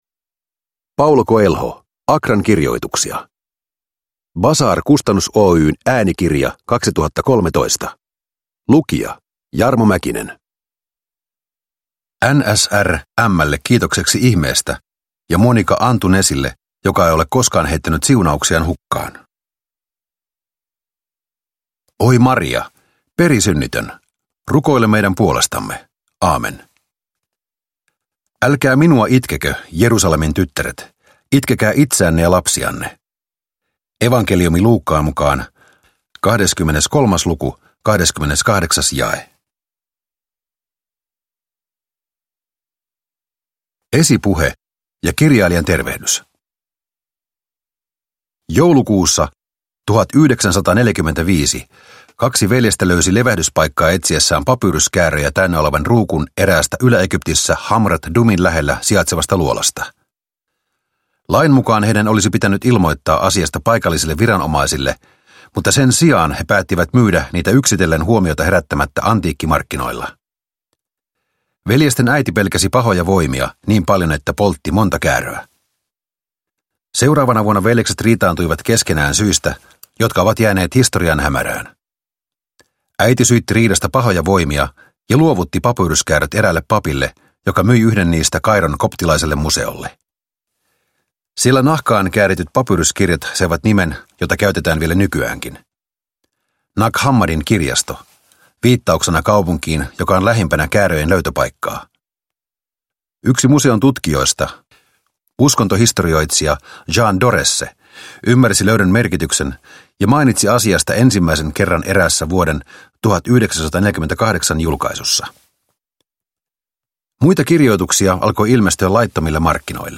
Accran kirjoitukset – Ljudbok – Laddas ner